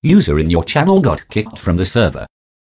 user.left.kicked.server.wav